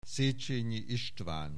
Aussprache Aussprache
SZECHENYIISTVAN.wav